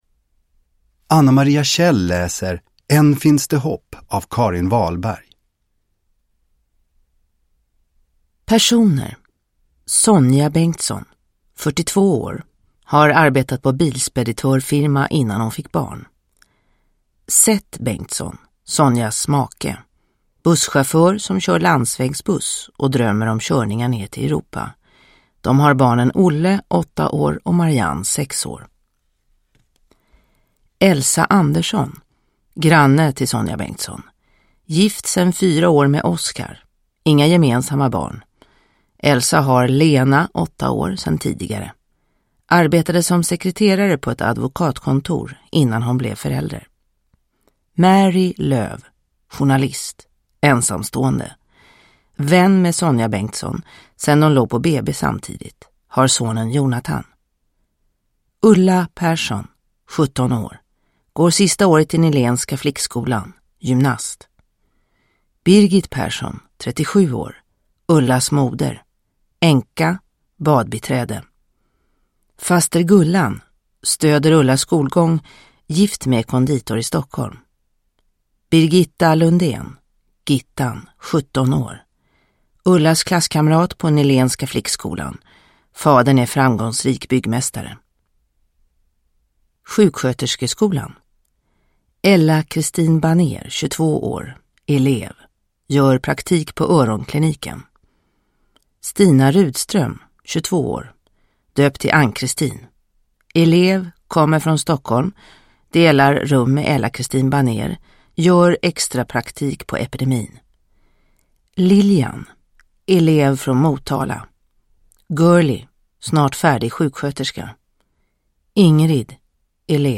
Än finns det hopp – Ljudbok – Laddas ner